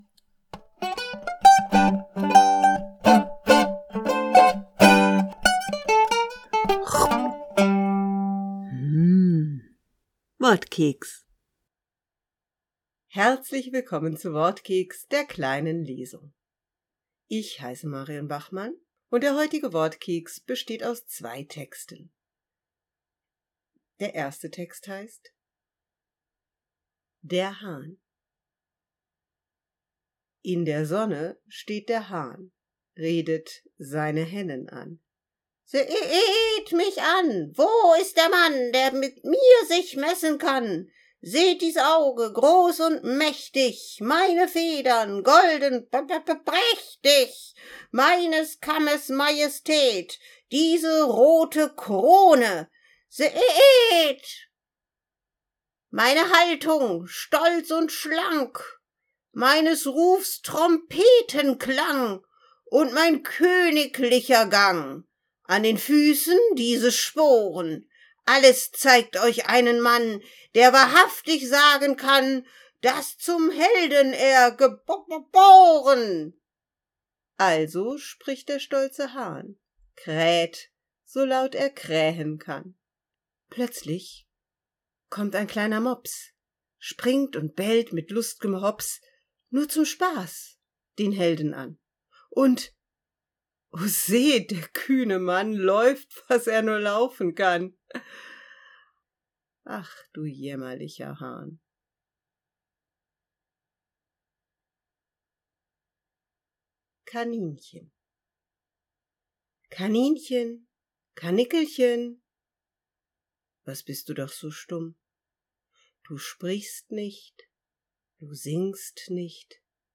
Kleine Lesung mit Hahn und Kaninchen.